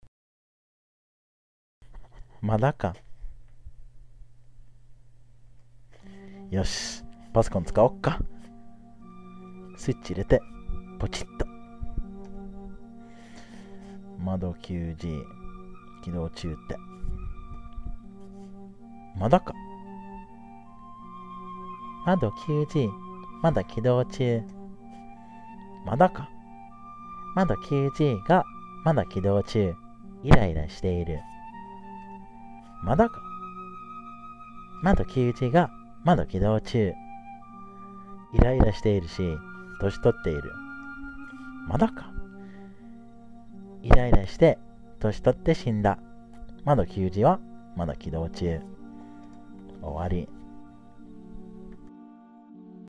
The background music is by Kevin McLeod (thanks!).